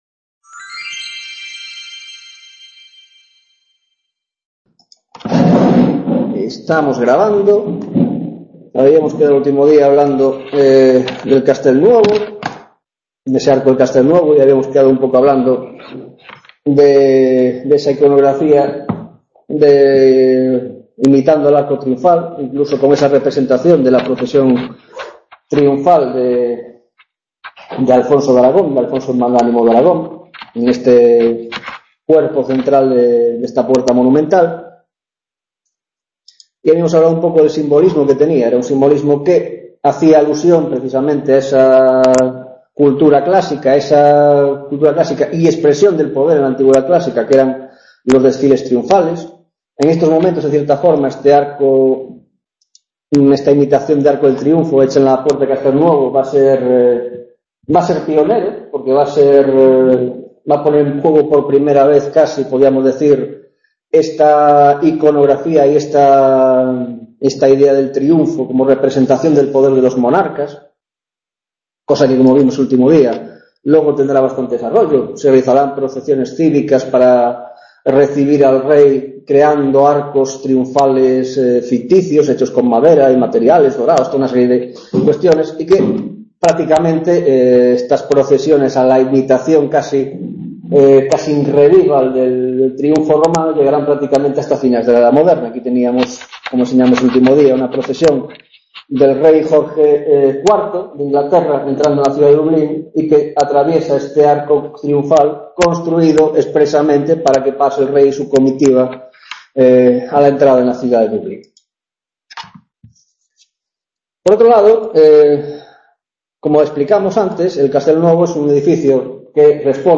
4ª Tutoria de la asignatura Arte y Poder en la Edad Moderna - Cortes Italianas, 2ª parte